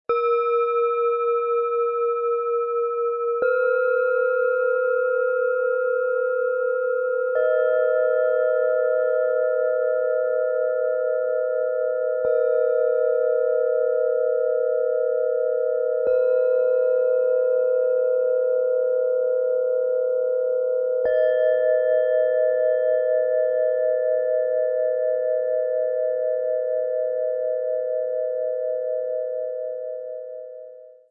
Mini-Klangschalen-Set zum Hören, Lauschen, Klangpädagogik und Fantasie fördern
Diese kleine Schale ist fein und geistig anregend.
Mit dem Sound-Player - Jetzt reinhören lässt sich der Original-Klang dieser feinen Schalen live anhören - genau so, wie sie im Set erklingen.
Ein passender Klöppel wird mitgeliefert - er bringt die Schalen sanft und klar zum Klingen.
Tiefster Ton: Mond
Bengalen Schale, Matt, 12,1 cm Durchmesser, 6,6 cm Höhe
Mittlerer Ton: Delfin
Höchster Ton: Wasserstoffgamma